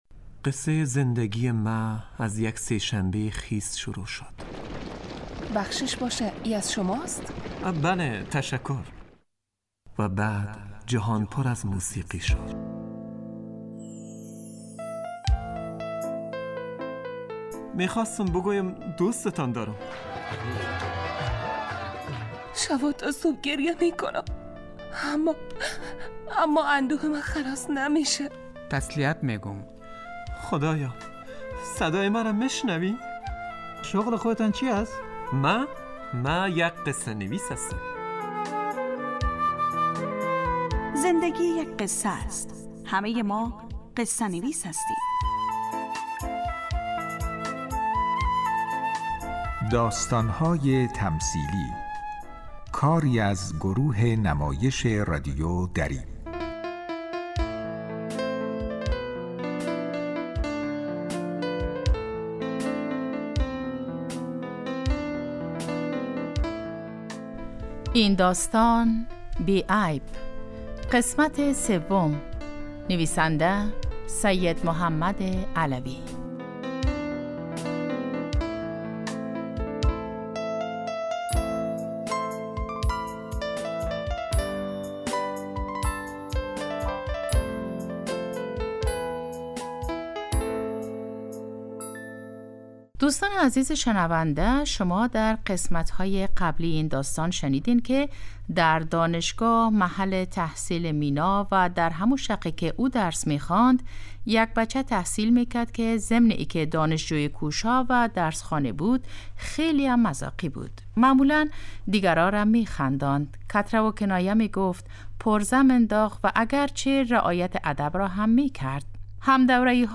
داستانهای تمثیلی نمایش 15 دقیقه ای هستند که روزهای دوشنبه تا پنج شنبه ساعت 03:25عصربه وقت افغانستان پخش می شود.